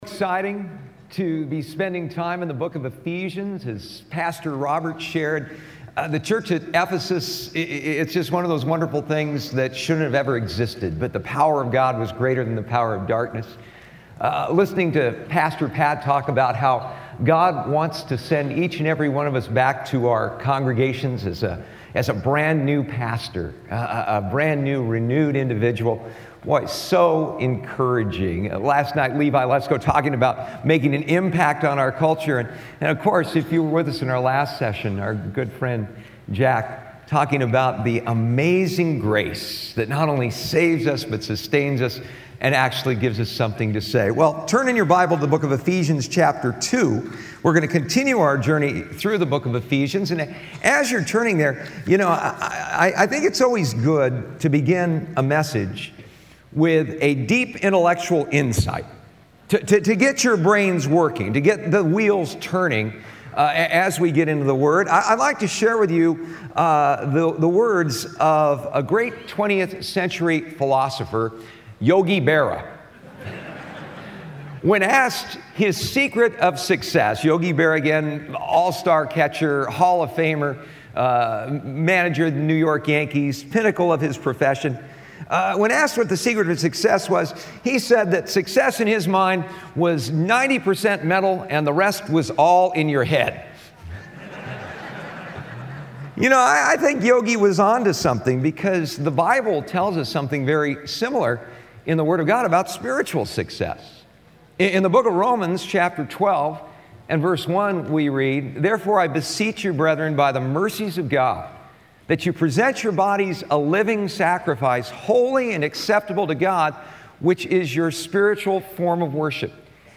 at the 2016 SW Pastors and Leaders Conference